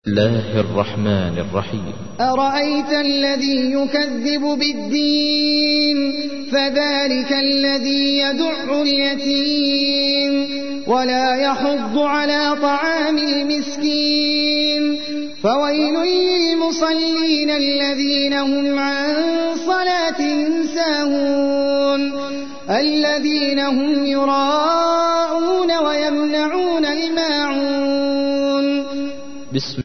تحميل : 107. سورة الماعون / القارئ احمد العجمي / القرآن الكريم / موقع يا حسين